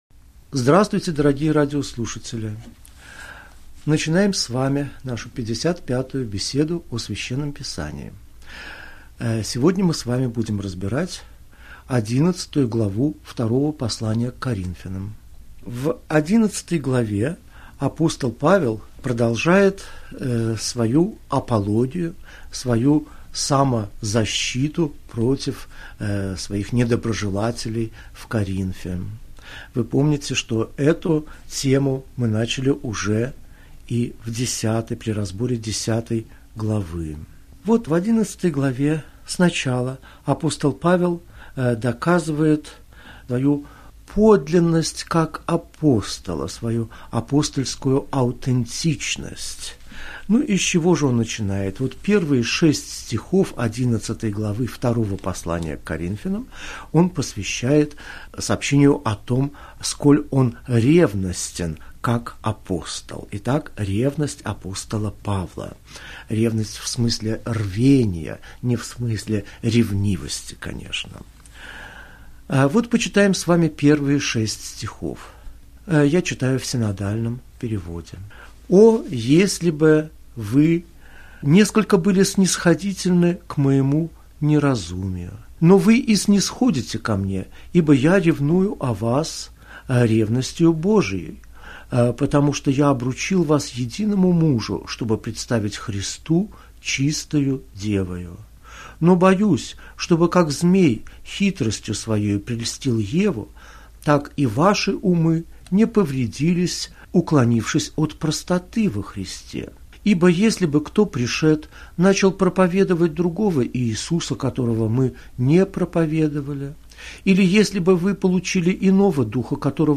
Аудиокнига Беседа 55. Второе послание к Коринфянам. Глава 11, стихи 1 – 15 | Библиотека аудиокниг